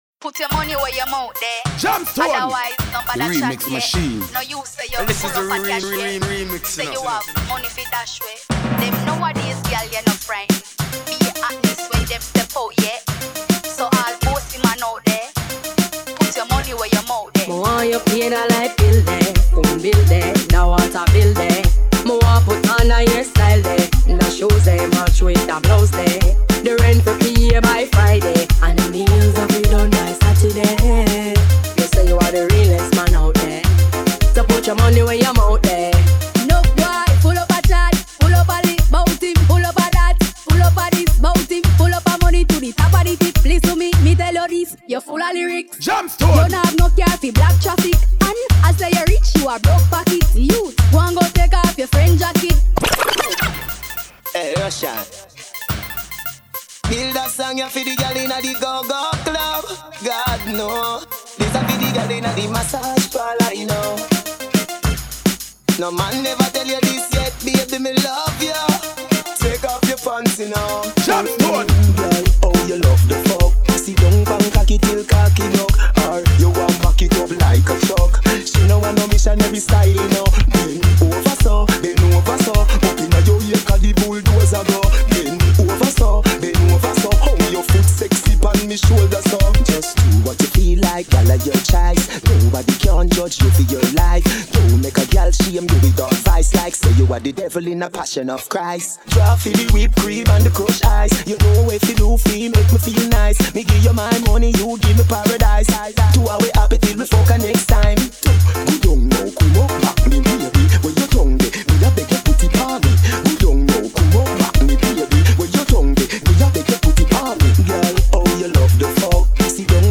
Vocal tracks